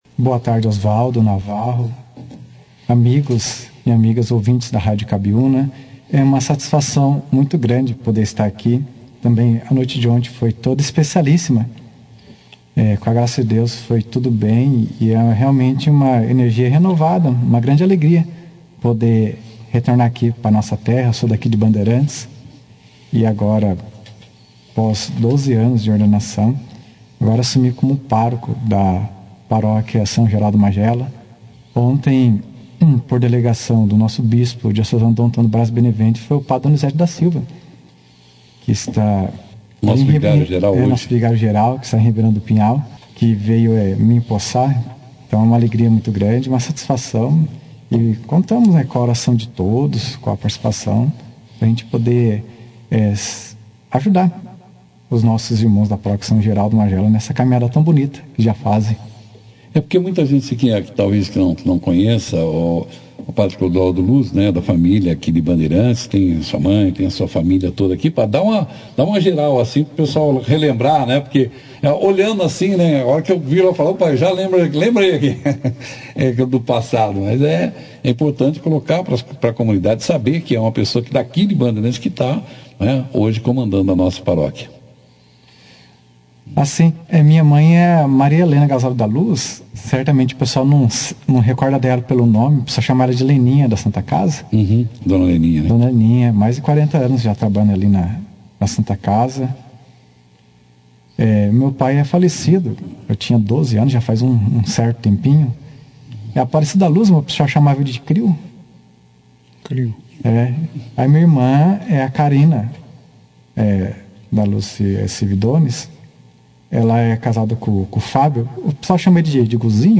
participou da 2ª edição do jornal Operação Cidade, onde compartilhou suas experiências e refletiu sobre sua jornada no sacerdócio. Ele também expressou a expectativa de liderar a paróquia em sua comunidade de origem. https